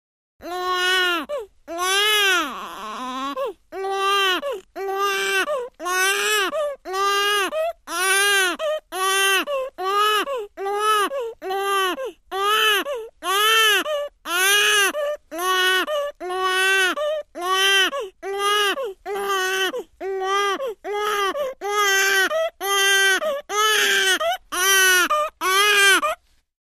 Newborn Baby Wail Long